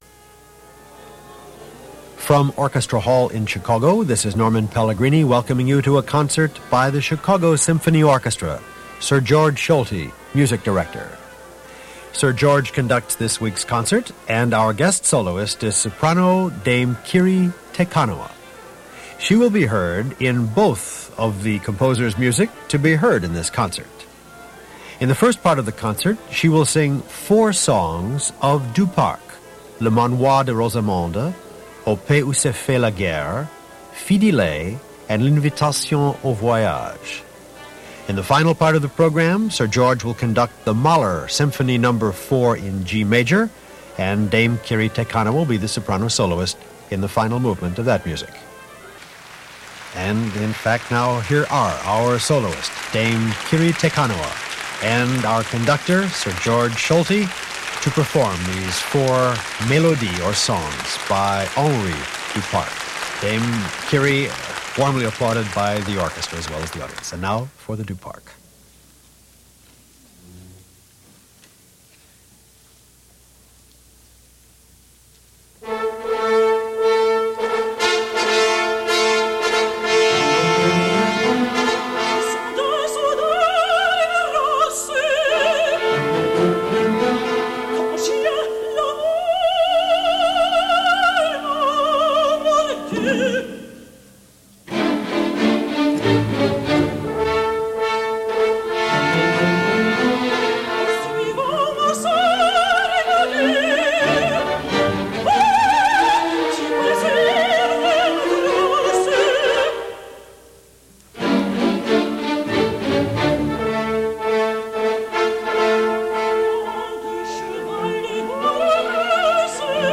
an evening of sumptuous music making